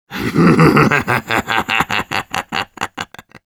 Голосовые команды медика - Official TF2 Wiki | Official Team Fortress Wiki
Medic_laughevil04_ru.wav